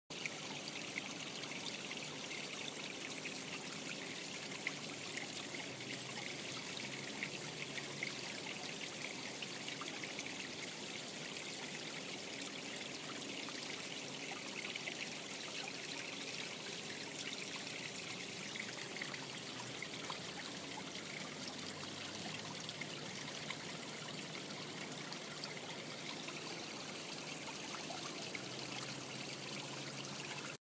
各种现场录音 " 流
描述：流动的现场记录。
Tag: 小溪 流动 河流 环境 运行时 流水声 小溪 液体 自然 现场记录 放松 涓涓细流 记录